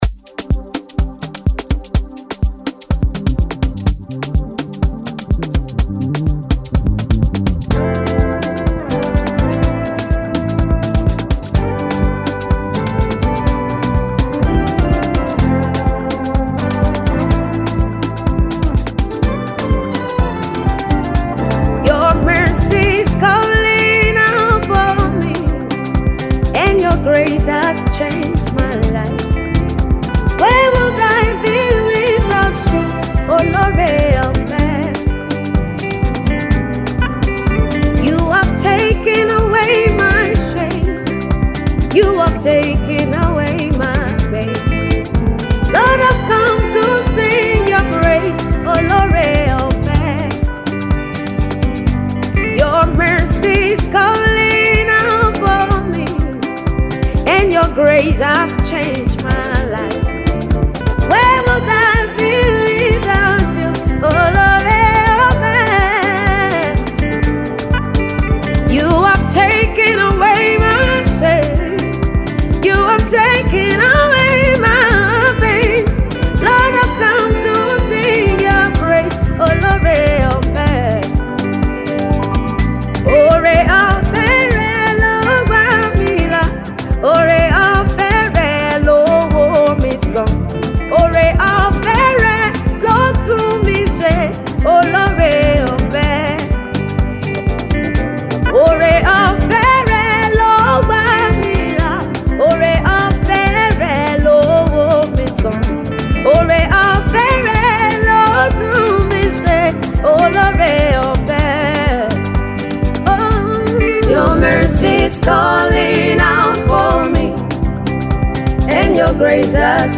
Rising gospel artiste